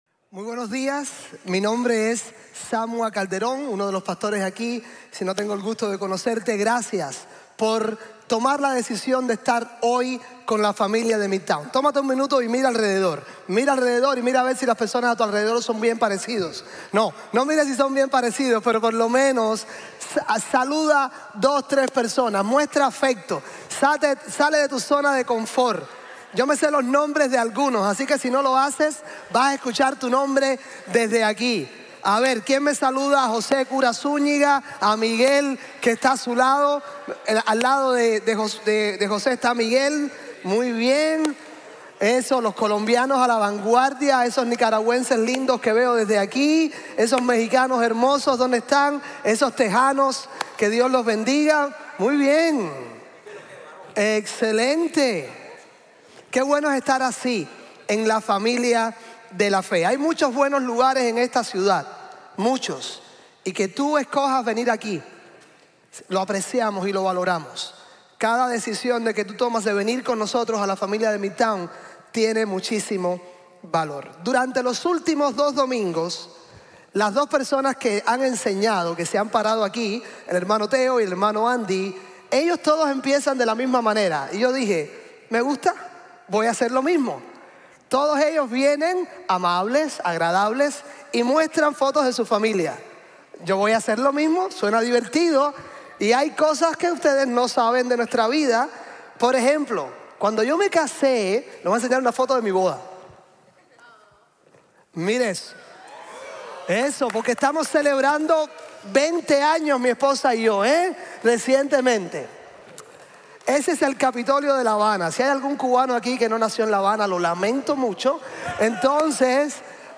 Nosotros… importamos para Jesús | Sermon | Grace Bible Church